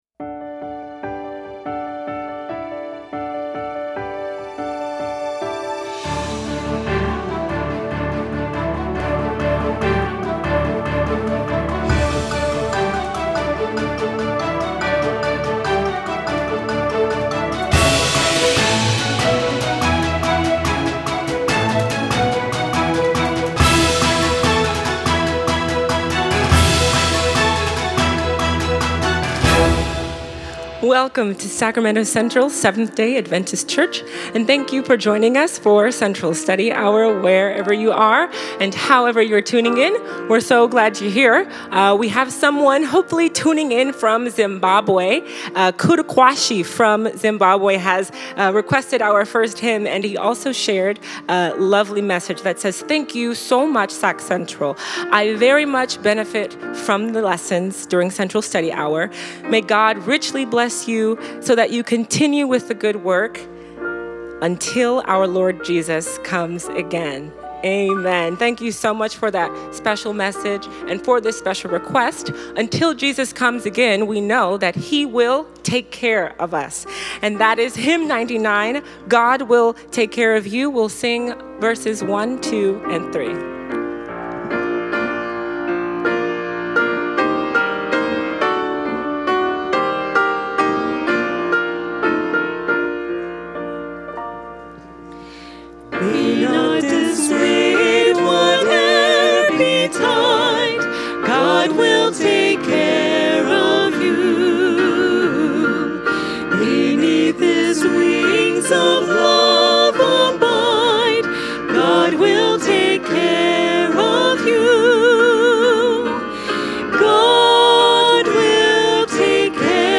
Sacramento Central Seventh-day Adventist Church